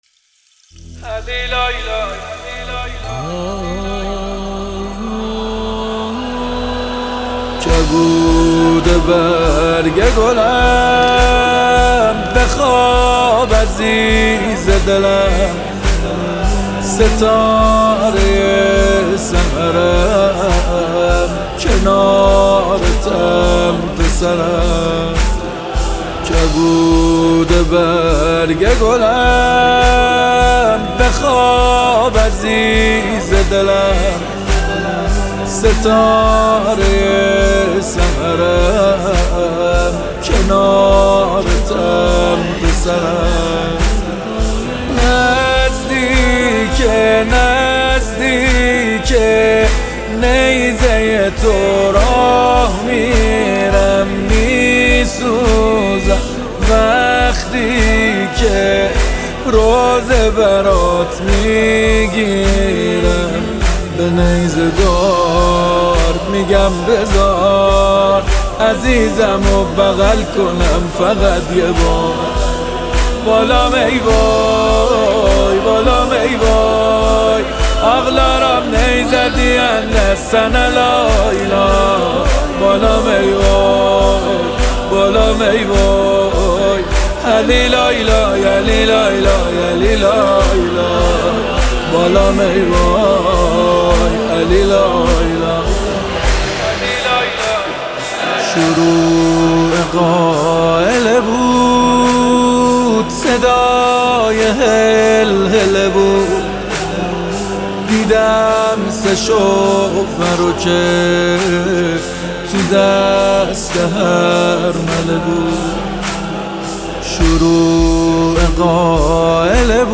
نماهنگ مذهبی جدید
نماهنگ ویژه شب هفتم محرم